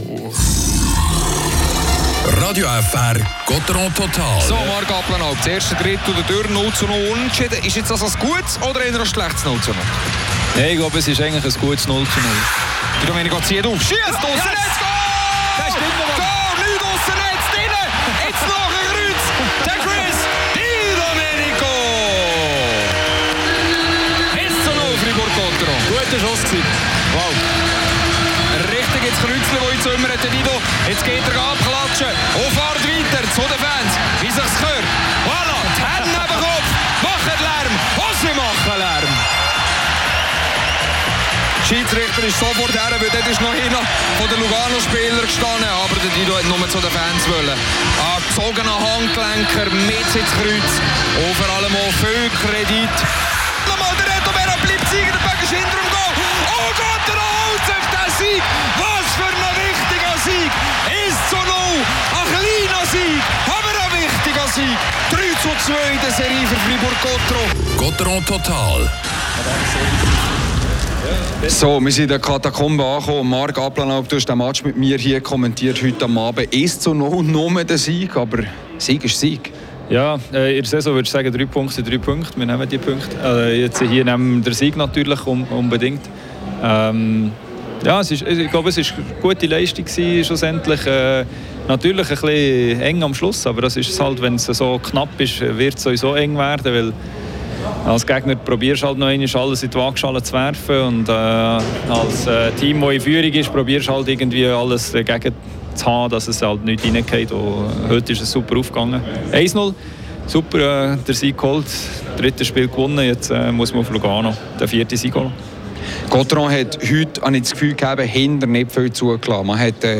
und das Interview mit Raphael Diaz.